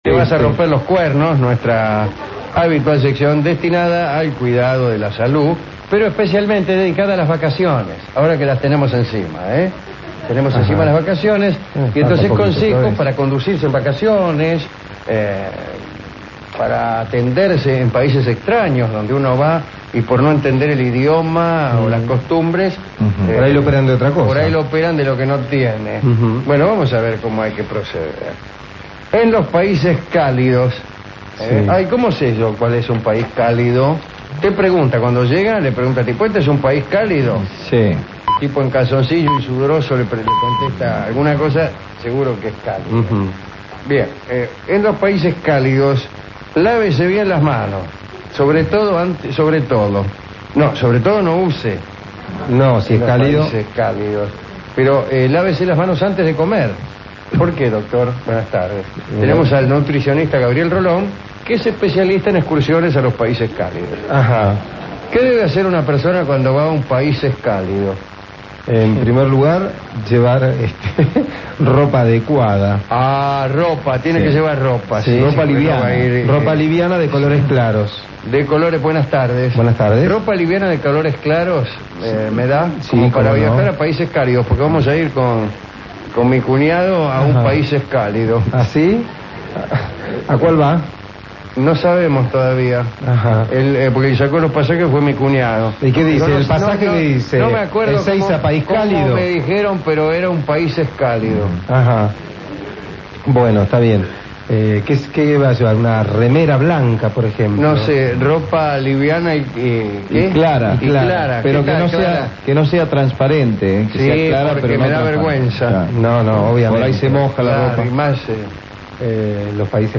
Programa grabado.